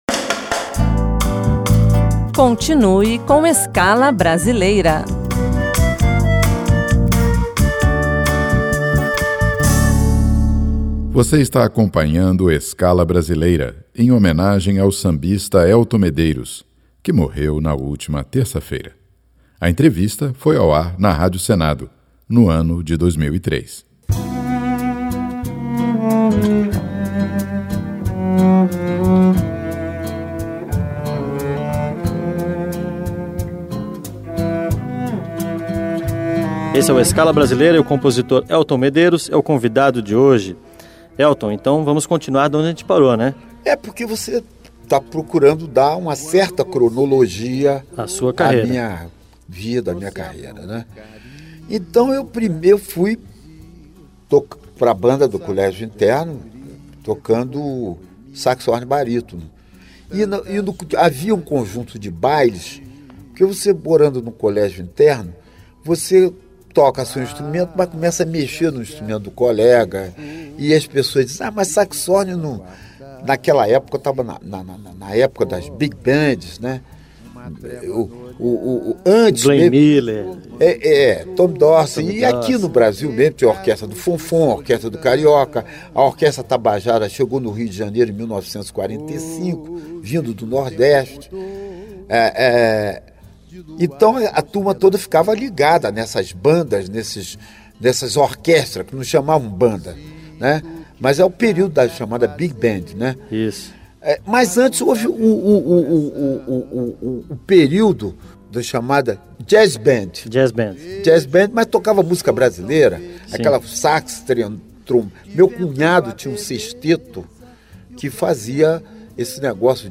Nesta semana o programa Escala Brasileira reprisa a entrevista com Elton Medeiros, que foi ao ar em 2003.